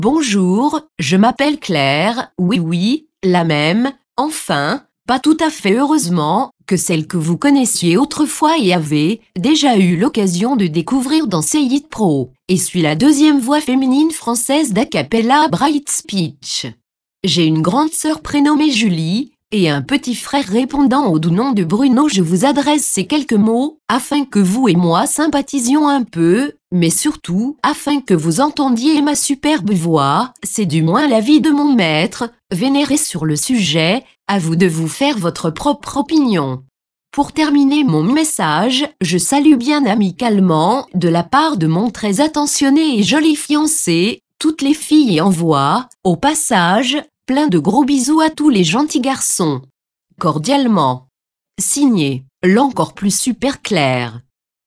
Texte de démonstration lu par Claire, deuxième voix féminine française d'Acapela Infovox Desktop Pro
Écouter la démonstration de Claire, deuxième voix féminine française d'Acapela Infovox Desktop Pro